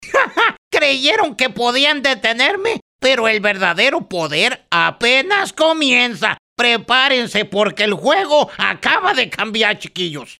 Male
Character / Cartoon
EspañOl: Voz DináMica Y Expresiva Con Amplia Capacidad Para Crear Personajes úNicos, Divertidos O Intensos. English: Dynamic And Expressive Voice With Strong Character Range, From Playful To Dramatic Personalities.